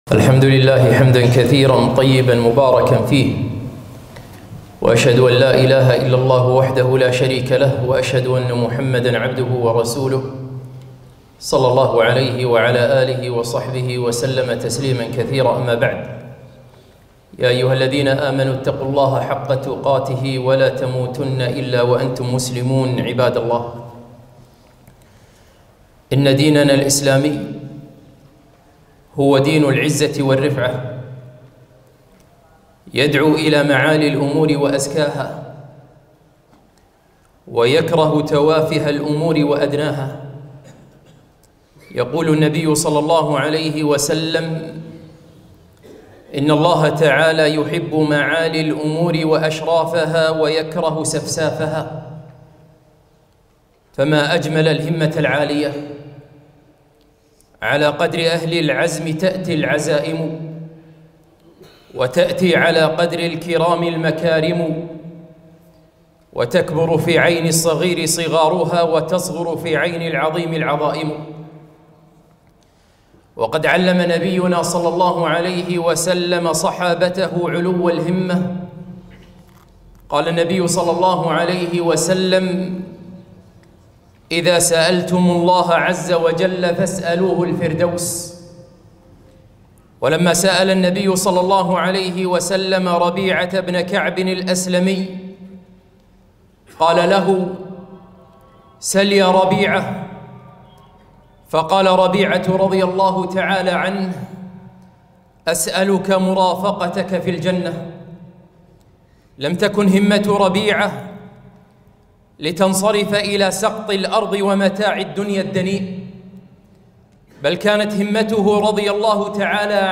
خطبة - الهمة يا شباب بدأ كأس العالم